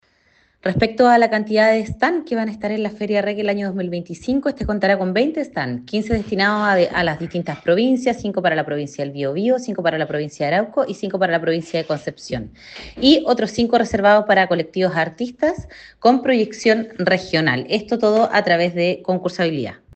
La seremi se refirió a los cupos habilitados y se asegurarán espacios para todas las provincias.